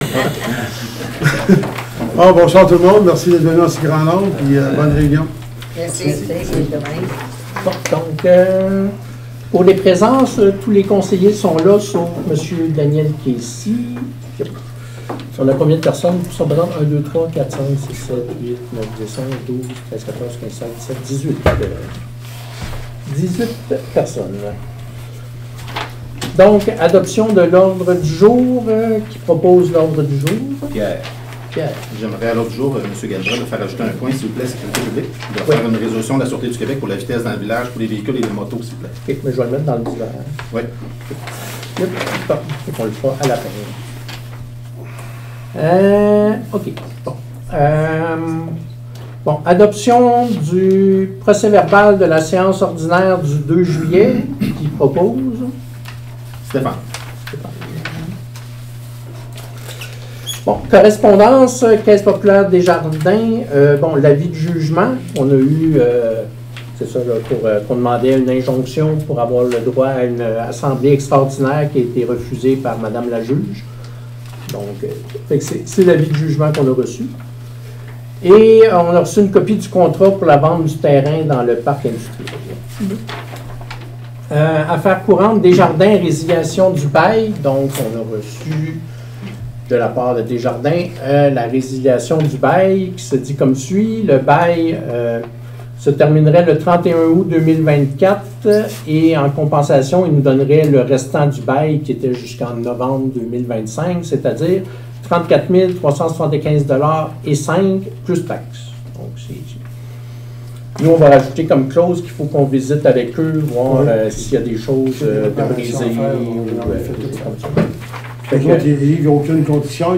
Fichier audio – Séance ordinaire du 12 août 2024 (31 MB)